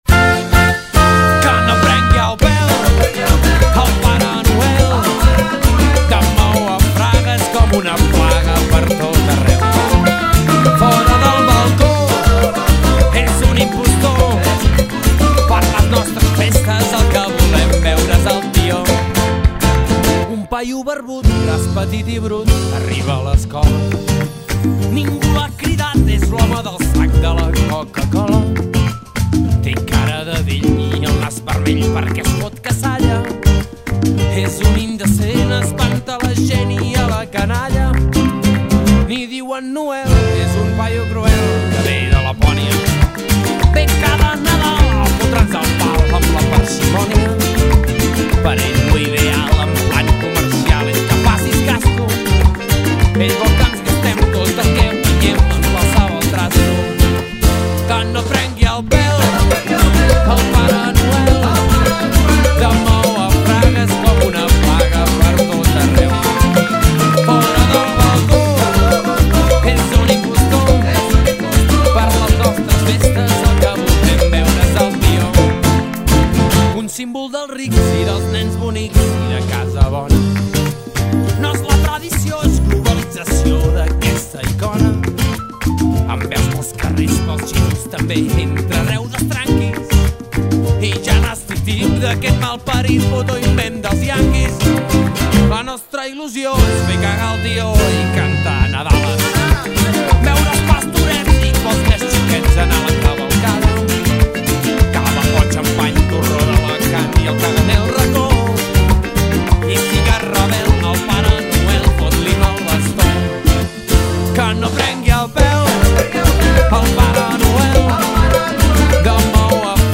rumba catalana